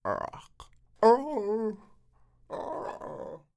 怪物恐怖的声音 " 06451 吸血鬼慢速撞击的尖叫声
描述：吸血鬼打了一声呻吟
标签： 尖叫 reature 吸血鬼 SFX 怪物 呻吟 声音
声道立体声